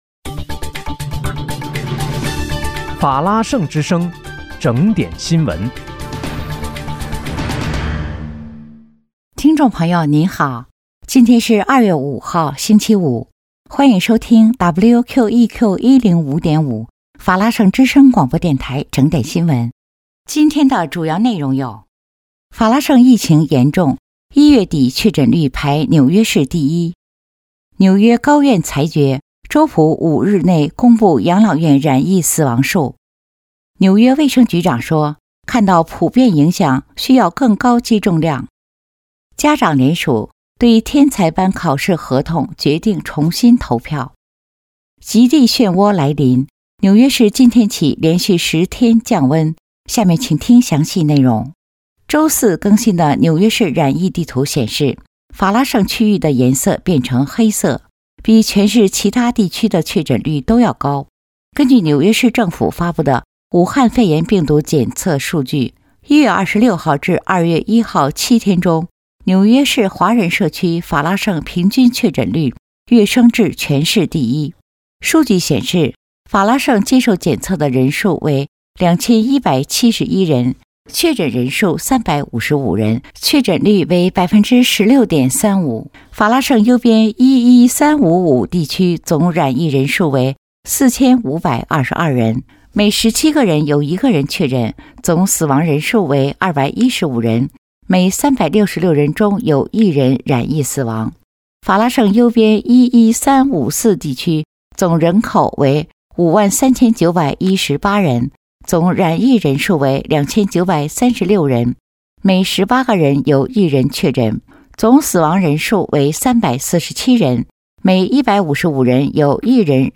2月5日（星期五）纽约整点新闻